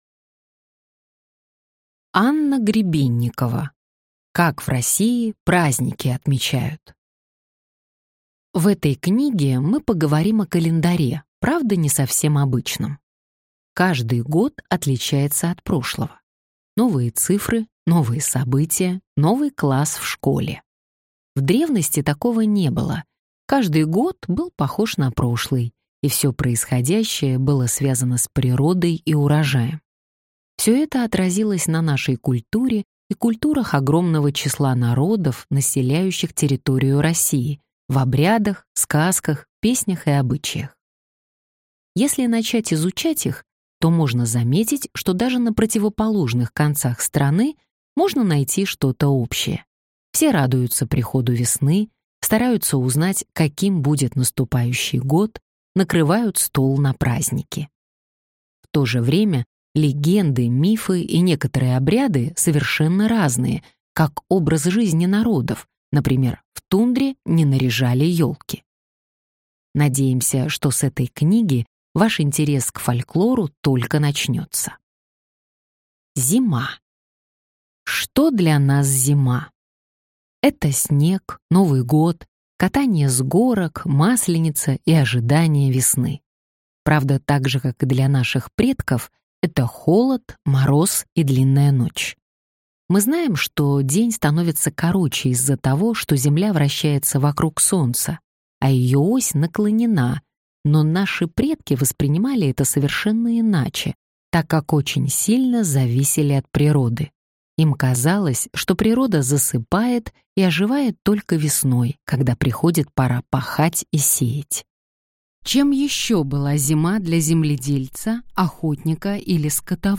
Аудиокнига Как в России праздники отмечают?